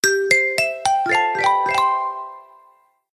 Волшебное SMS Сообщение